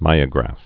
(mīə-grăf)